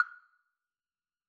Coffee2.wav